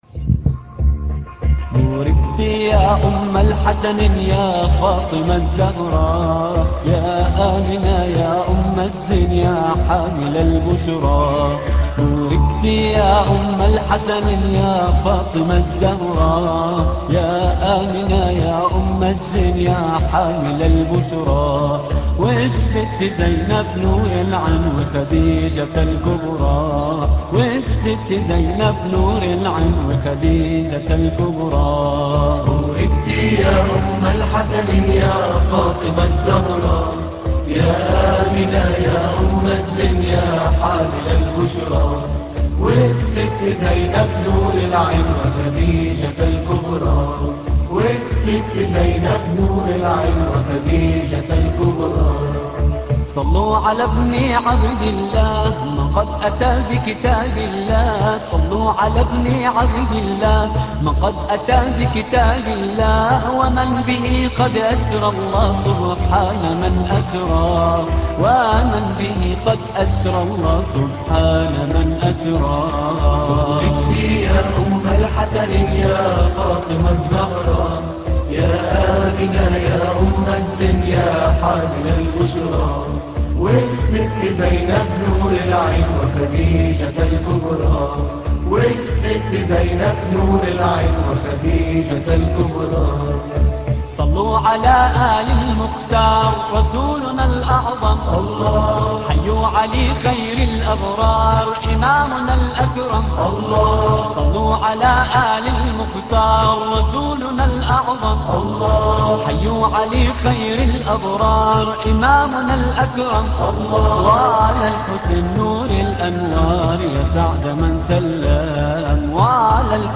بوركت يا أم الحسنين ـ نغمة الرست - لحفظ الملف في مجلد خاص اضغط بالزر الأيمن هنا ثم اختر (حفظ الهدف باسم - Save Target As) واختر المكان المناسب